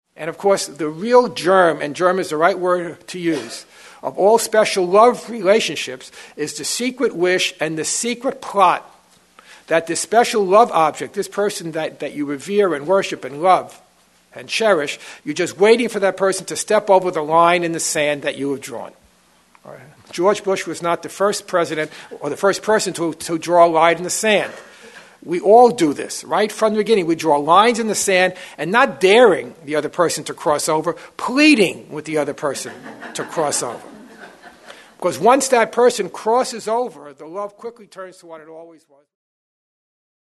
The discussion in this workshop focuses on how special relationships originate in, and are sustained by, our need to defend against the pain of our guilt over the seeming separation from God. The insidious and painful dynamics of specialness are explored as they develop on interpersonal and international levels. The undoing of our guilt through forgiveness is explained in the context of our moving from separate interests to shared interests.